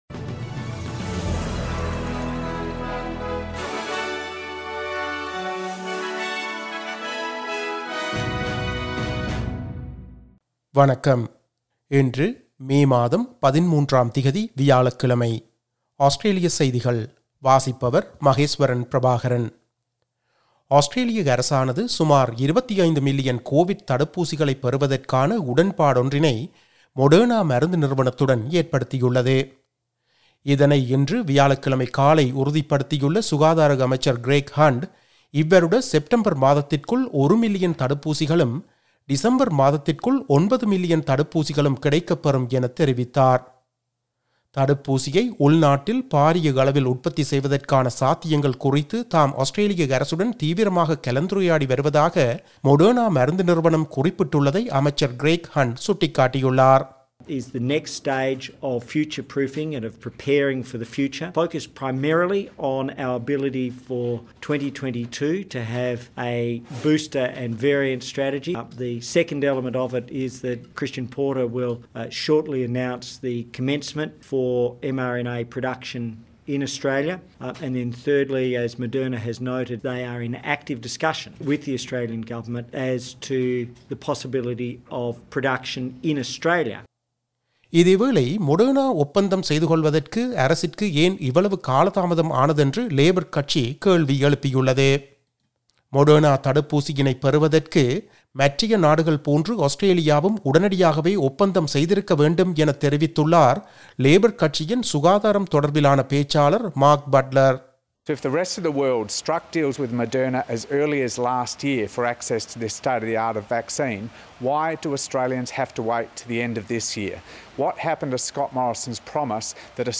Australian news bulletin for Thursday 13 May 2021.